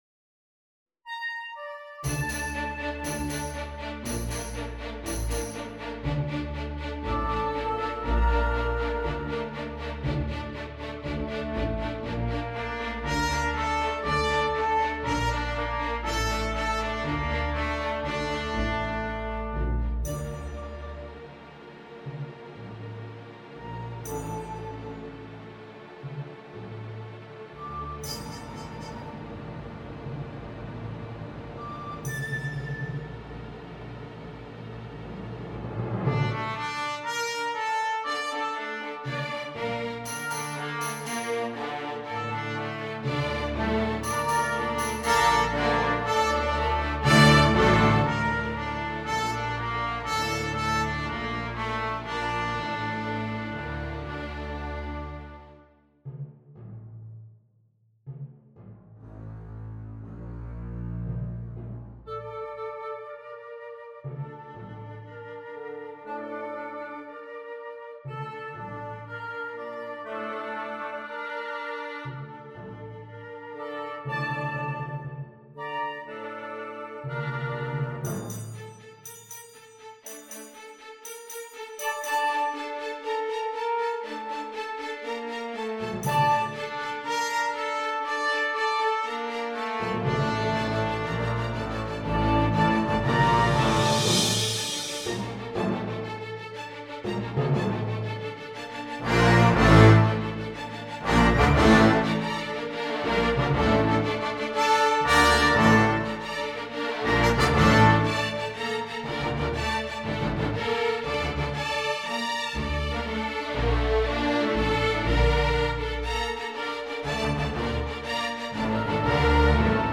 Full Orchestra
2[1.2/picc].2.2.1 - 2.2.3[1.2.3 btbn].0 timp,perc(1),str
Percussion: s. cym., tamb., tri.
It has been described as being cinematic in feel.
1. 4/4 Allegro moderato (2:31) 0:00
2. 6/8 Lento - Allegretto (2:20) 2:32
3. 8/8 Tango - Poco Maestoso (2:30) 4:56
4. 9/8 Allegro (1:52) 7:27